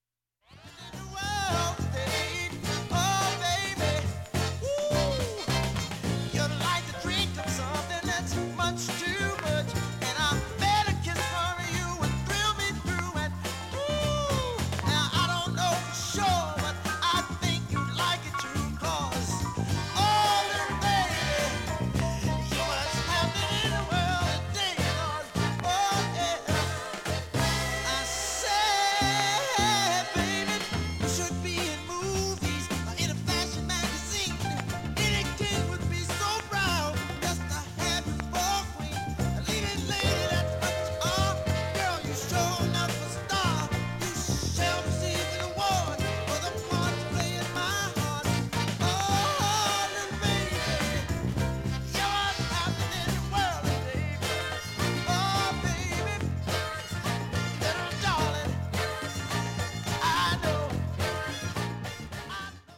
盤面きれいです、音質良好全曲試聴済み。
レーベル特有のアーシーなサーフェス曲間に
かすかですが入る箇所が味があっていいと思います
６０秒の間に周回プツ出ますがかすかです。
◆ＵＳＡ盤Stereo, Reissue